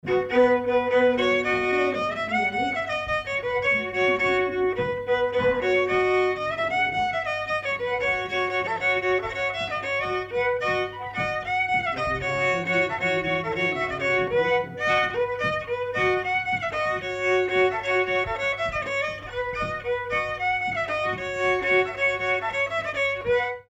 Danse
circonstance : bal, dancerie
Pièce musicale inédite